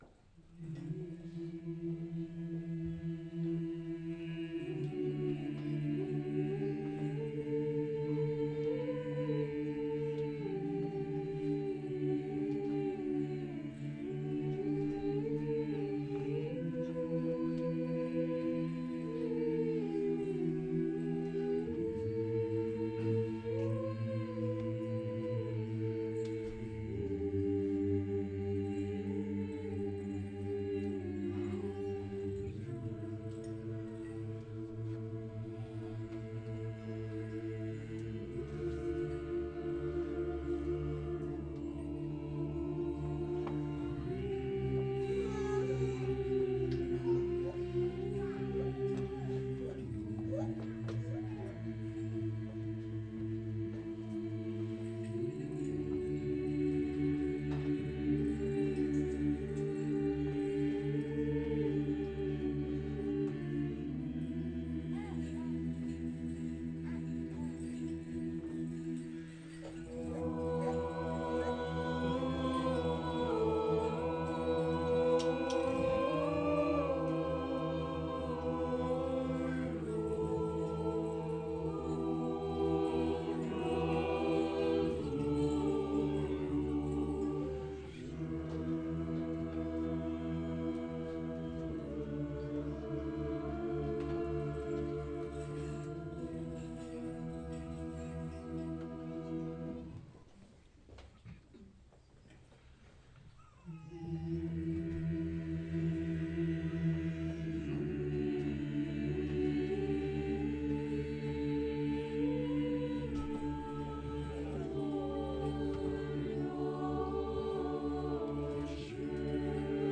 We will post the full liturgy sound file later tonight or tomorrow, but the Cherubic Hymn from today was just so beautiful that we had to post it immediately!